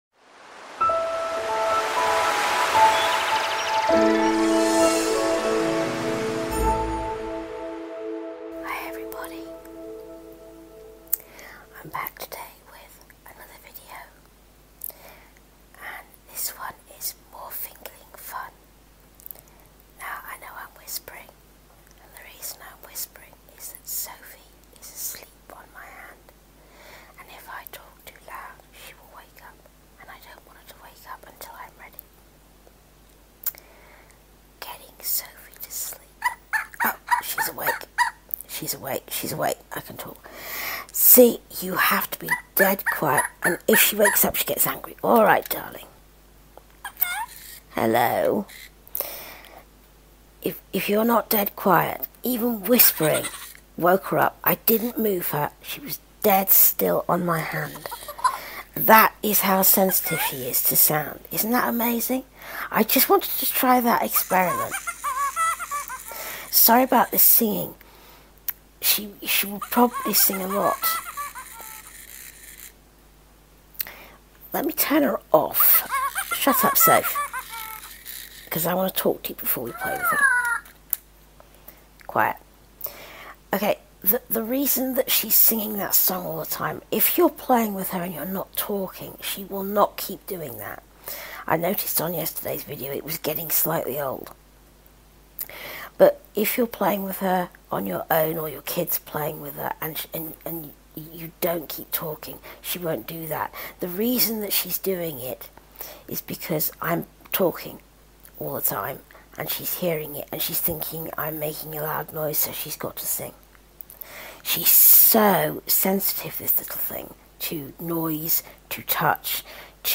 Another piece of toy audio for you today, this time with my second fingerling, the pink one named Sophie. These little critters are great fun, except, as you shall hear, when you want them to go to sleep!